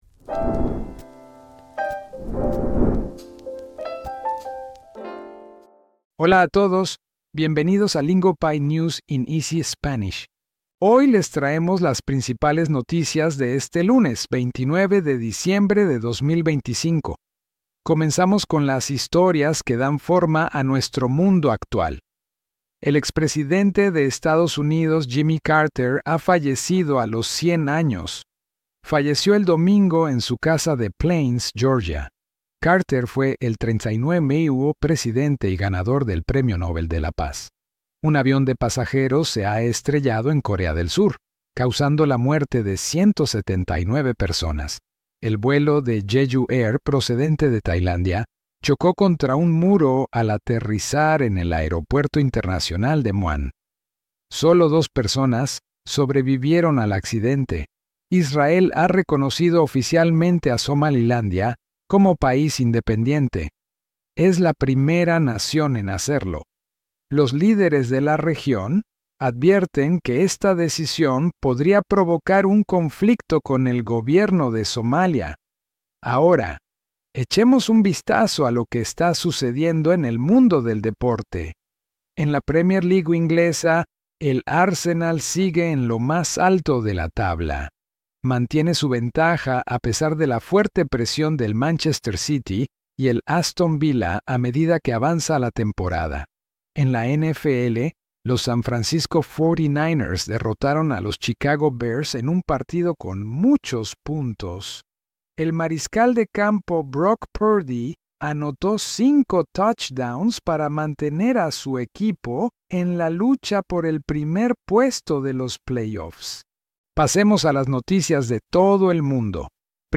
Lingopie’s News in Easy Spanish makes today’s headlines accessible in Spanish, designed for learners.